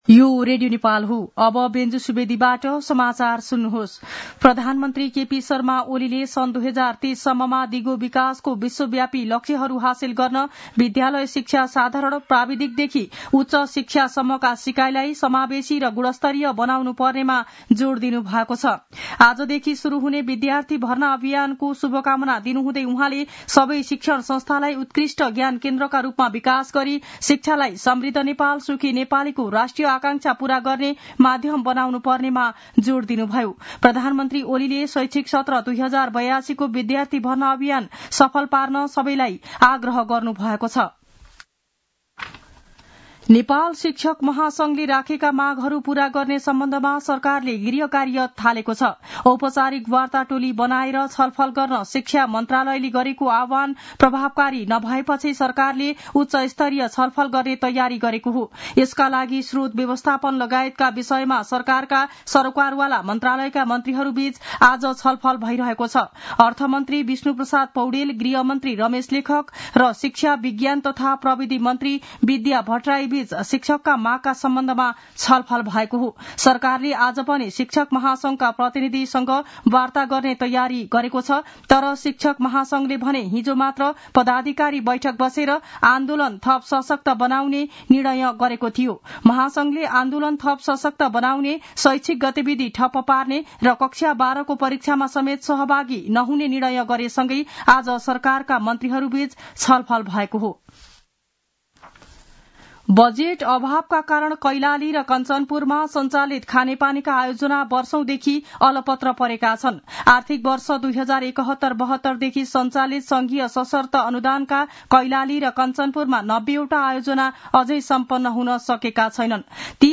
दिउँसो १ बजेको नेपाली समाचार : २ वैशाख , २०८२
1-pm-news-1-5.mp3